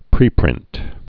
(prēprĭnt)